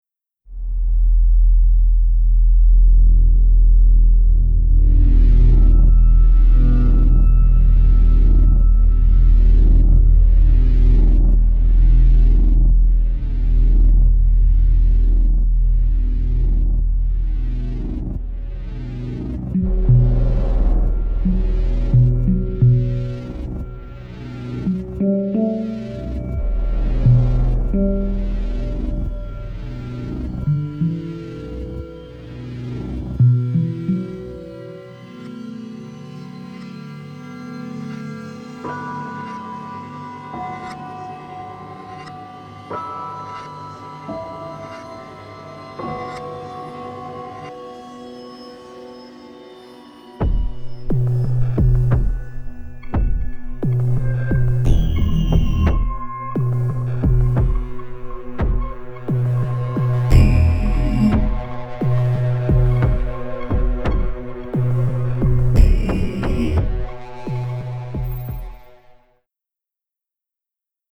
Another chilling highlight